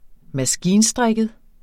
Udtale [ -ˌsdʁεgəð ]